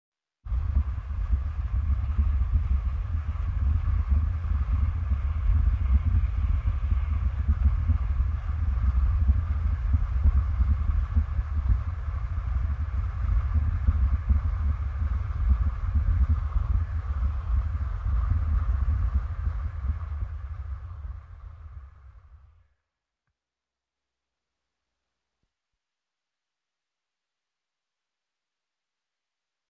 Звук костра, гудение пламени в печи, звук потрескивания языков пламени в камине можно слушать и скачать здесь.
Огонь в печи, слышно характерное гудение
ogon_v_pechi.mp3